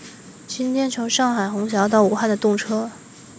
Nexdata/Mandarin_Speech_by_Mobile_Phone at main